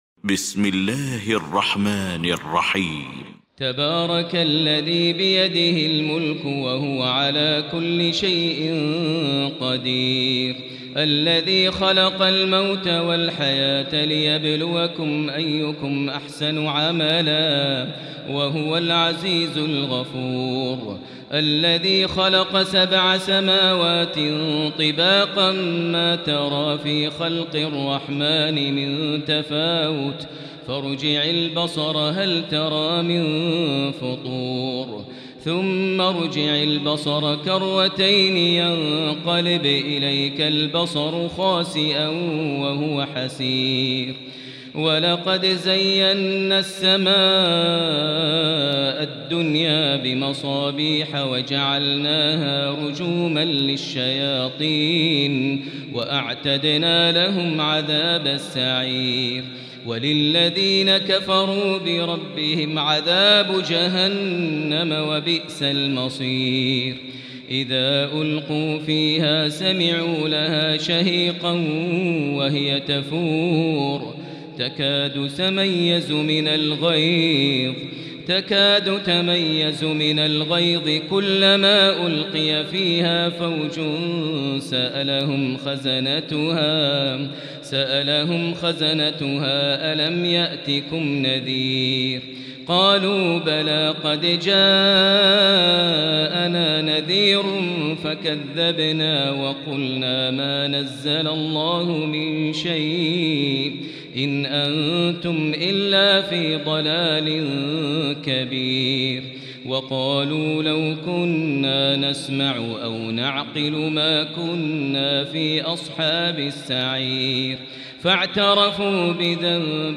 المكان: المسجد الحرام الشيخ: فضيلة الشيخ ماهر المعيقلي فضيلة الشيخ ماهر المعيقلي الملك